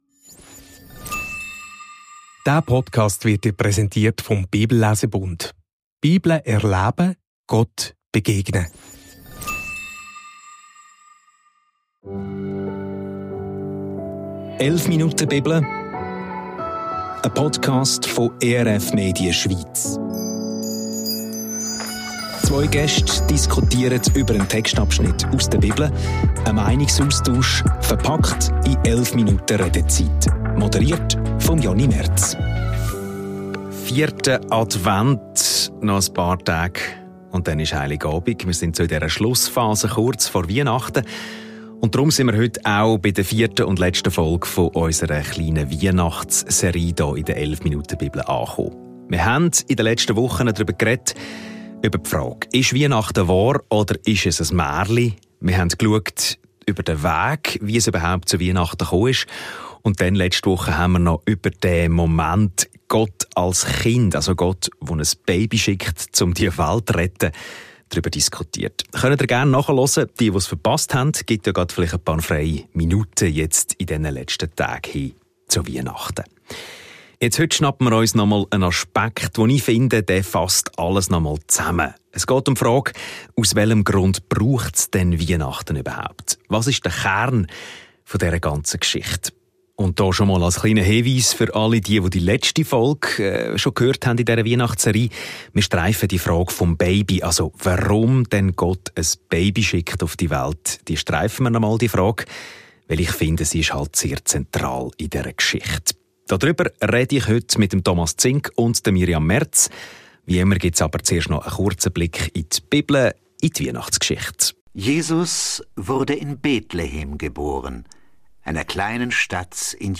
Leicht philosophisch, mit einem Hauch Theologie und einer Prise Humor.